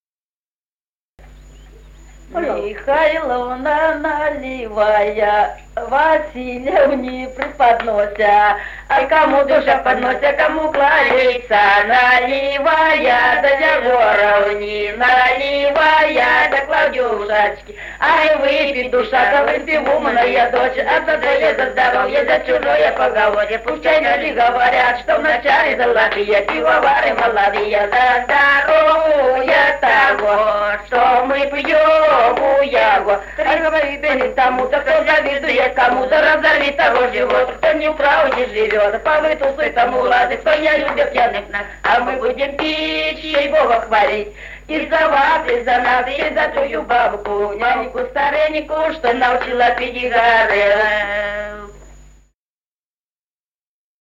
Народные песни Стародубского района
застольная «банкетная» песня.